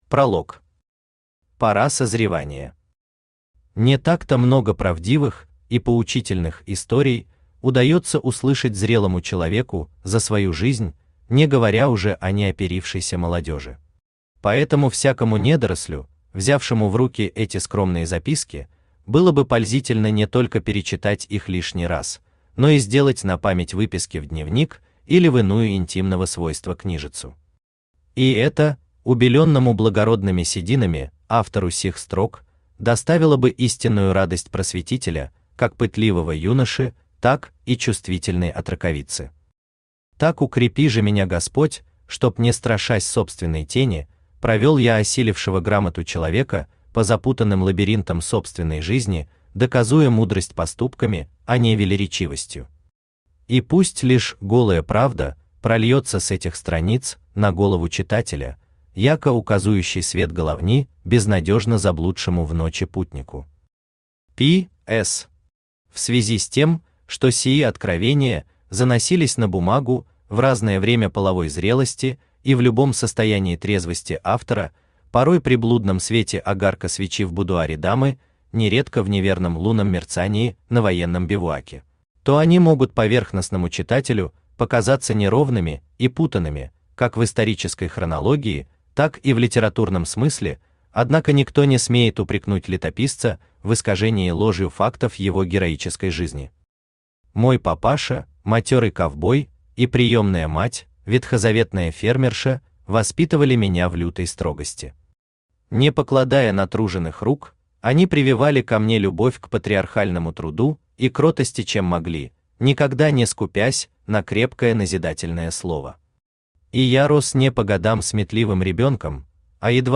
Книга 1 Автор Виктор Евгеньевич Рябинин Читает аудиокнигу Авточтец ЛитРес.